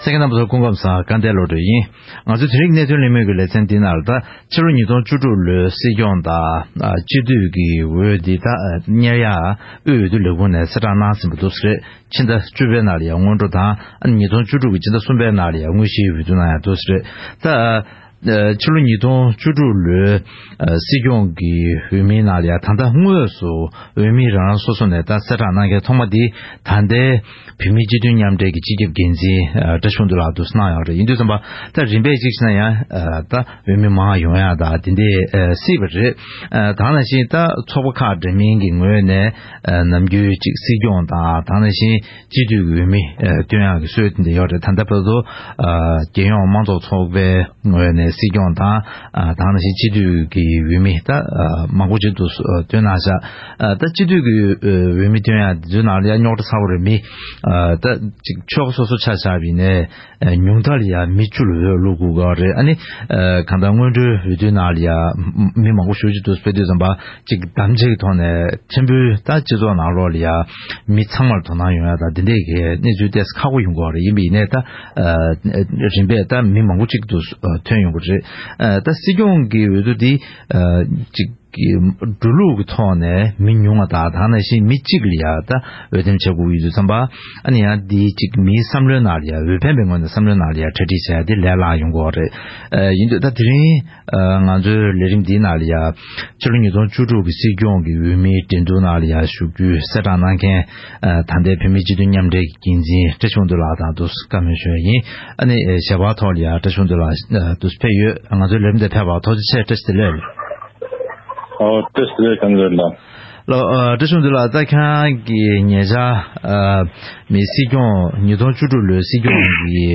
༄༅། །ཐེངས་འདིའི་གནད་དོན་གླེང་མོལ་གྱི་ལེ་ཚན་ནང་། དབུས་བོད་མིའི་སྒྲིག་འཛུགས་ཀྱི་དབུས་འོས་བསྡུ་ལྷན་ཁང་ནས་སྲིད་སྐྱོང་དང་སྤྱི་འཐུས་ཀྱི་འོས་བསྡུ་ཆེན་མོའི་ལས་རིམ་ཁག་གསལ་བསྒྲགས་གནང་བ་དང་བསྟུན། དེའི་སྐོར་གླེང་མོལ་ཞུས་པ་ཞིག་གསན་རོགས་གནང་།